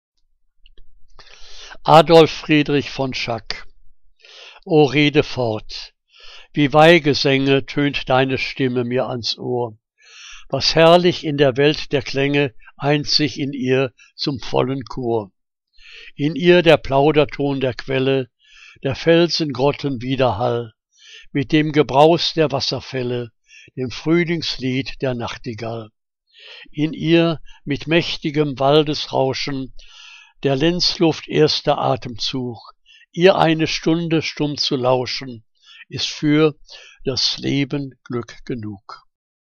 Liebeslyrik deutscher Dichter und Dichterinnen - gesprochen (Adolf Friedrich von Schack)